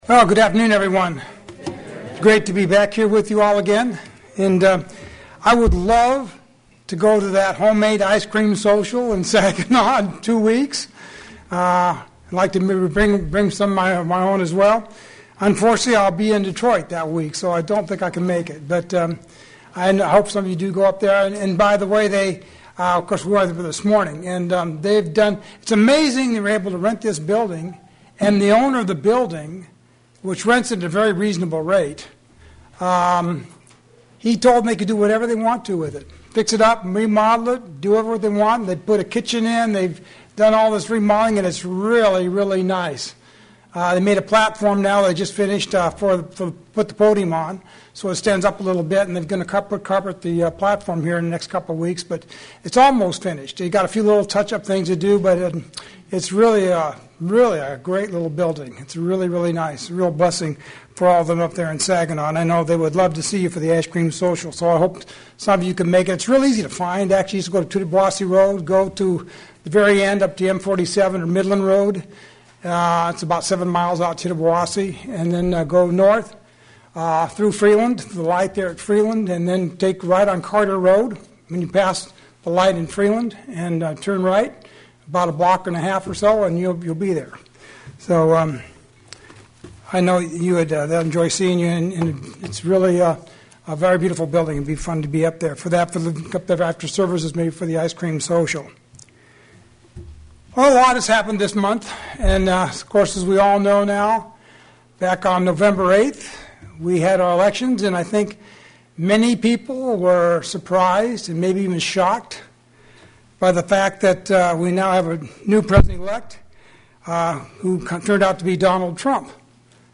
Could it be a famine of hearing the word of God? sermon Transcript This transcript was generated by AI and may contain errors.